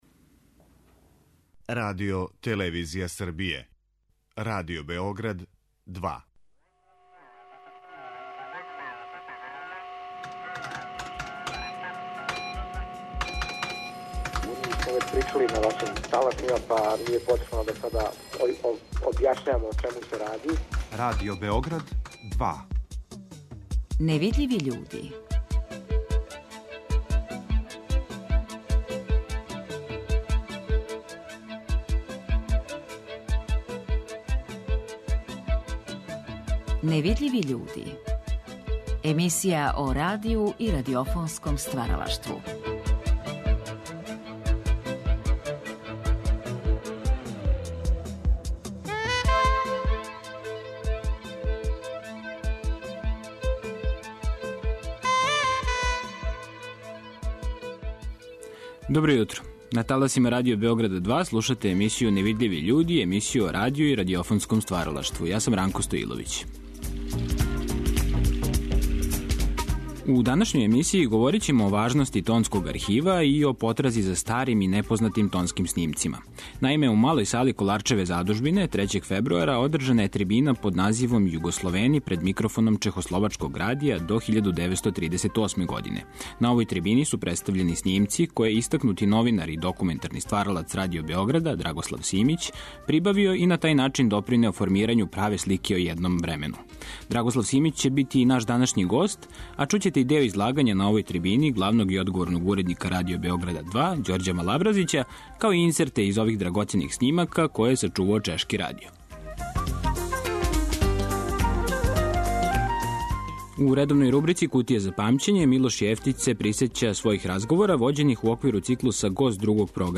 Одломке из неких од ових тонских записа који илуструју предратно расположење свесловенског јединства, имаћете прилике да чујете у овом издању емисије "Невидљиви људи"